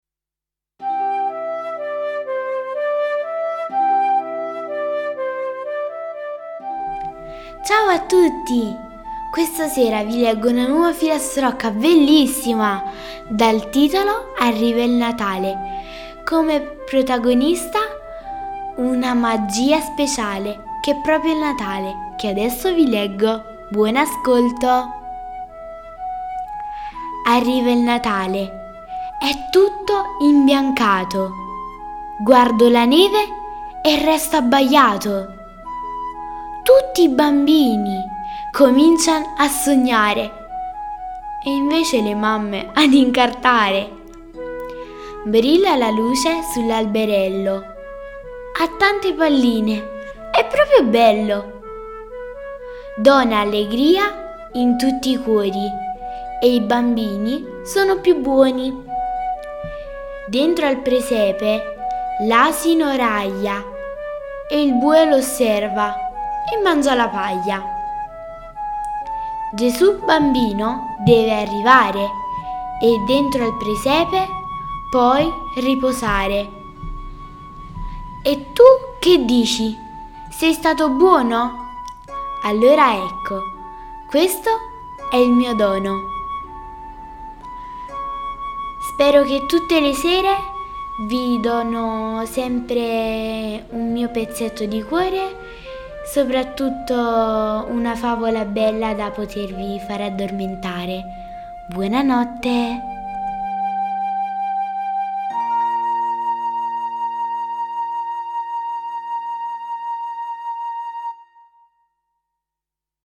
Leggi e ascolta la filastrocca “arriva il Natale”
Questa sera vi leggo una nuova filastrocca di Natale che narra della bellissima festa che si avvicina.